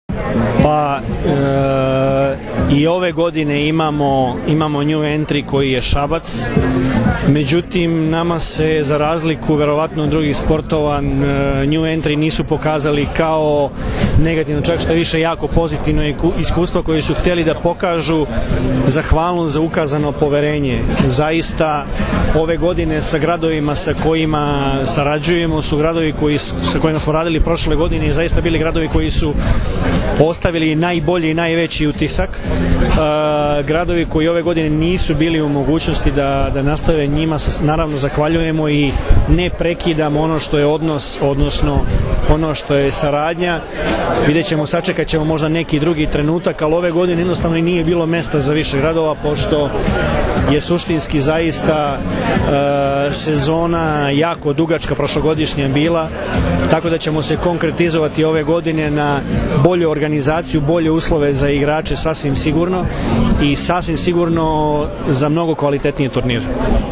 IZJAVA VLADIMIRA GRBIĆA